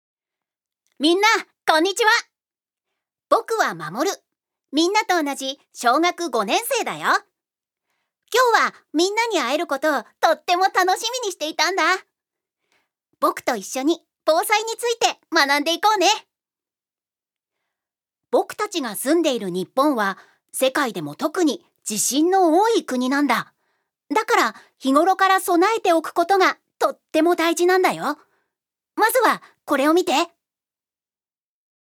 女性タレント
ナレーション６